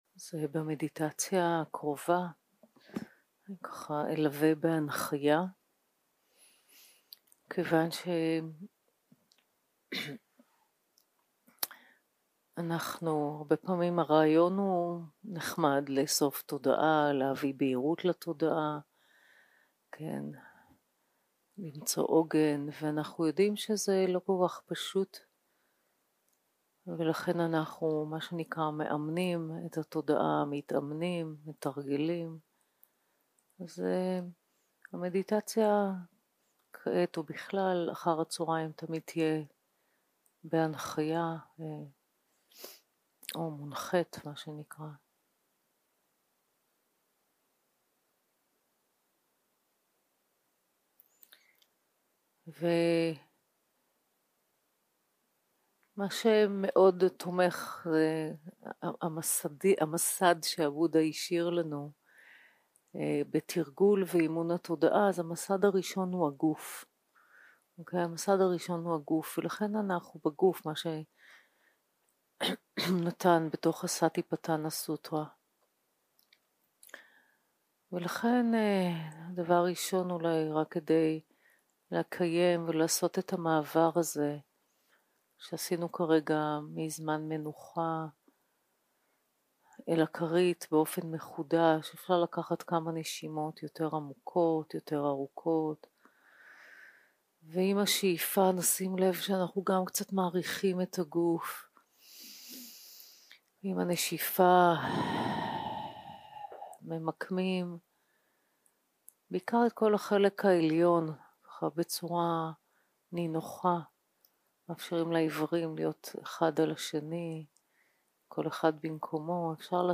יום 2 - הקלטה 2 - צהרים - מדיטציה מונחית - תשומת לב לגוף - יציבות ונינוחות 0:00 0:00 סוג ההקלטה: סוג ההקלטה: מדיטציה מונחית שפת ההקלטה: שפת ההקלטה: עברית